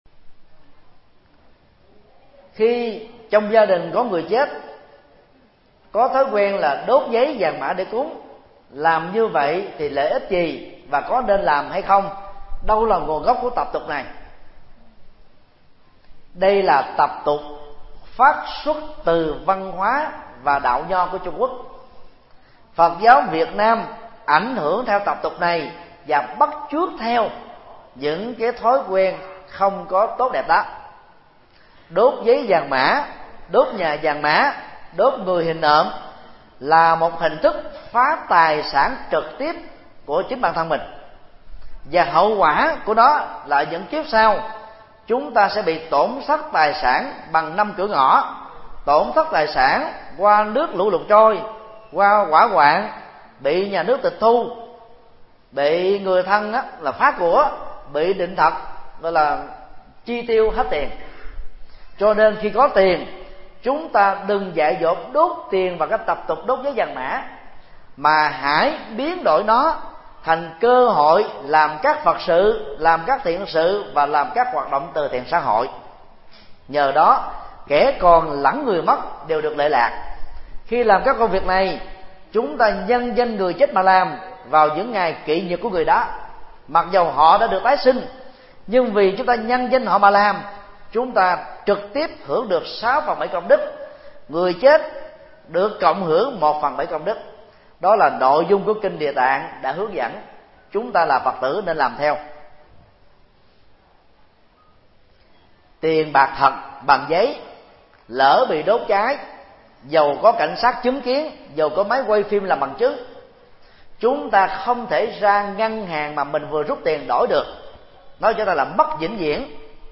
Vấn đáp: Nguồn gốc tập tục đốt vàng mã – Thích Nhật Từ